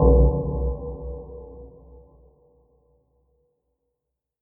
Categories Soundboard